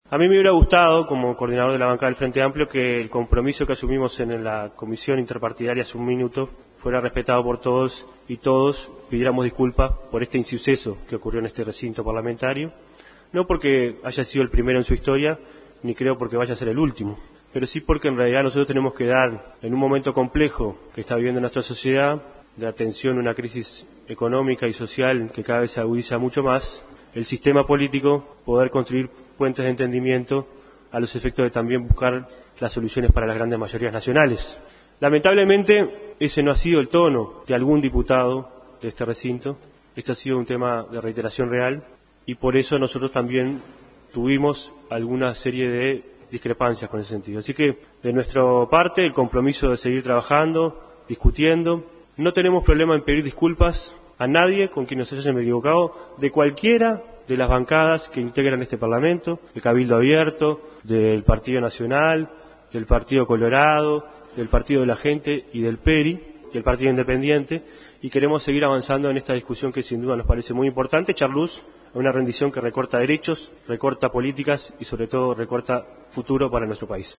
El diputado frenteamplista Daniel Caggiani, una vez que se calmó y retomaron con la discusión del proyecto de Rendición de Cuentas, pidió disculpas y dijo que le hubiera gustado que todos lo hagan.
CAGGIANI-pedido-de-disculpas.mp3